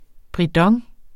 Udtale [ bʁiˈdʌŋ ]